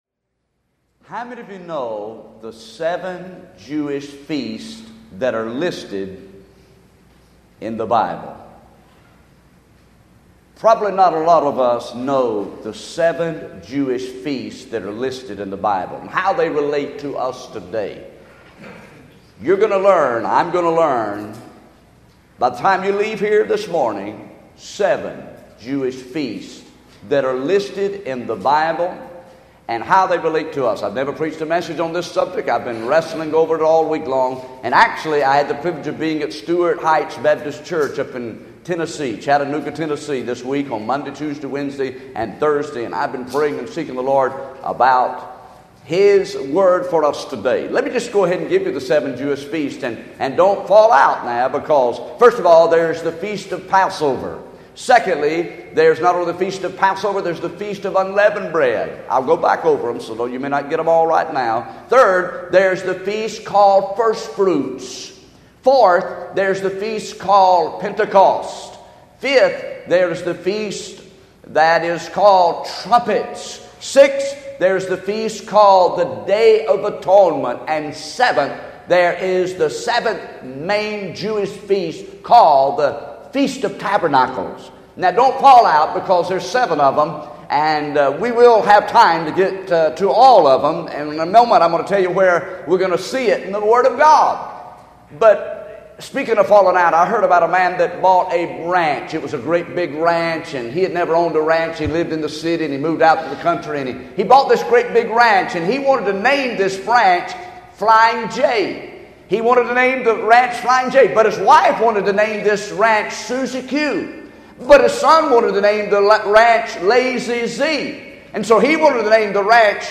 Sermons Archive | Page 33 of 38 | New Rocky Creek Baptist Church